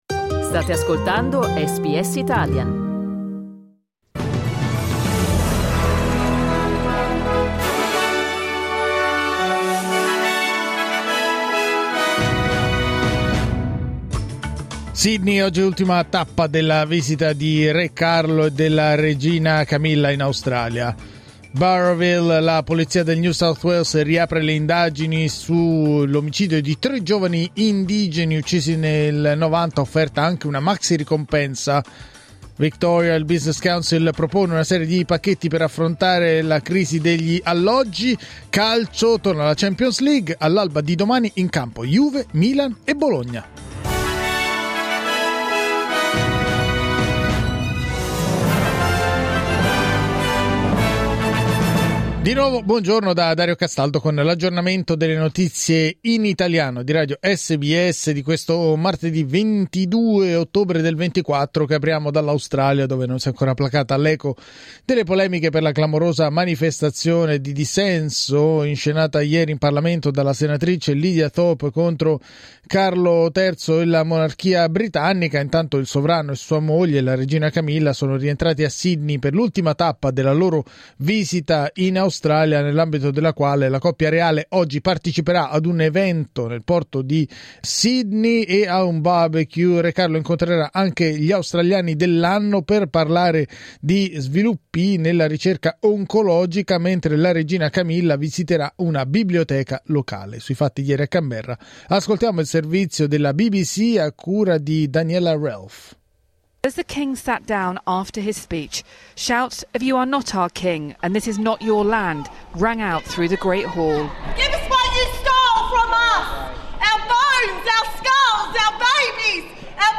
News flash martedì 22 ottobre 2024
L’aggiornamento delle notizie di SBS Italian.